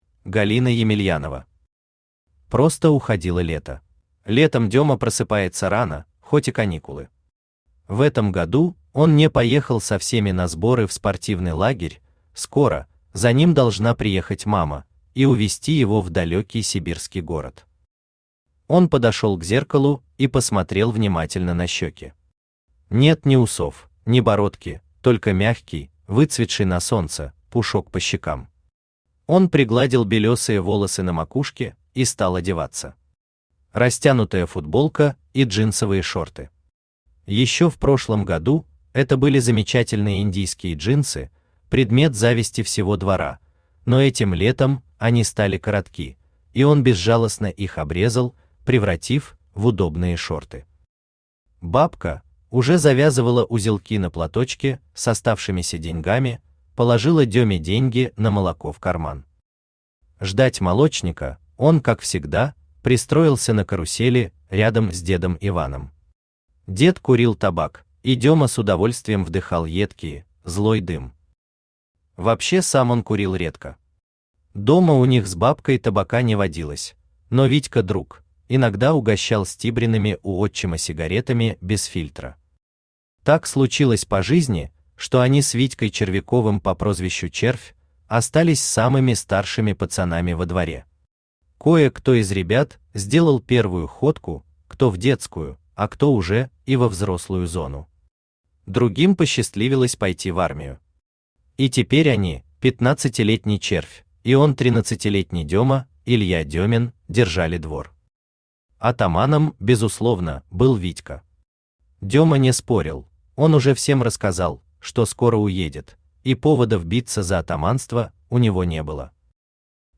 Аудиокнига Просто уходило лето | Библиотека аудиокниг
Aудиокнига Просто уходило лето Автор Галина Емельянова Читает аудиокнигу Авточтец ЛитРес.